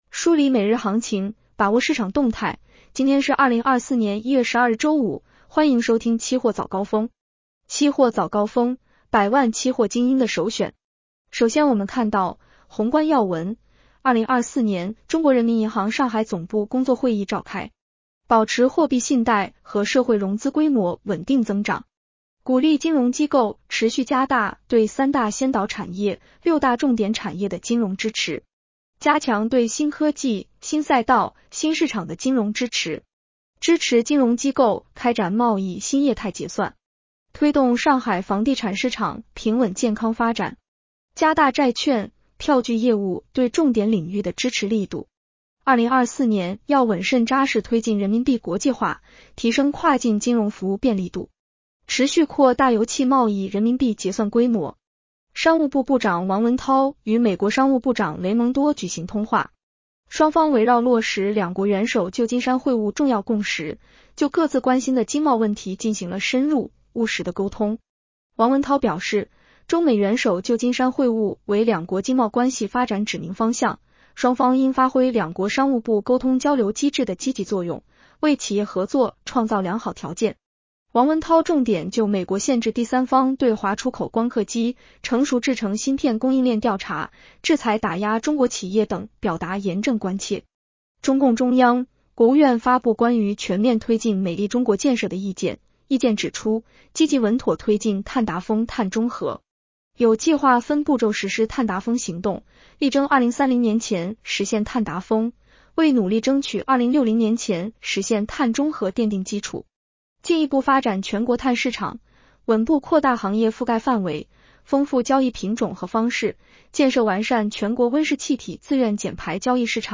【期货早高峰-音频版】 女声普通话版 下载mp3 宏观要闻 1. 2024年中国人民银行上海总部工作会议召开。